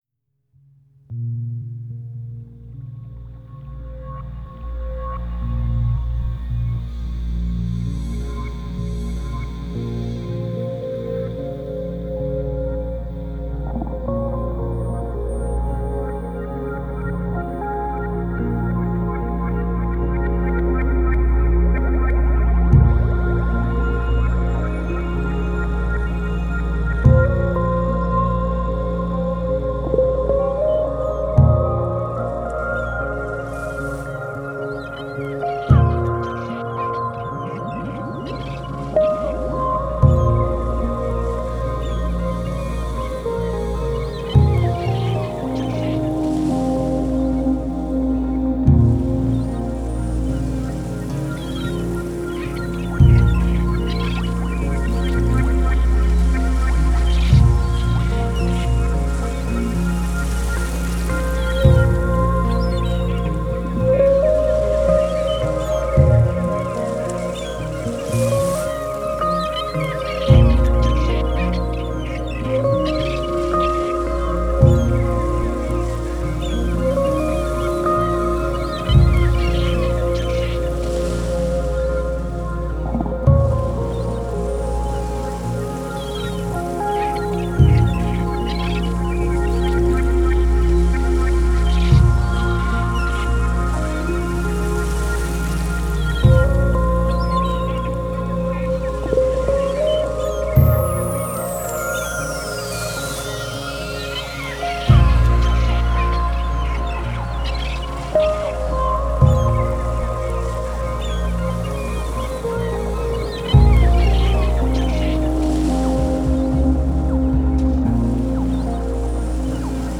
Genre: Lounge, Downtempo.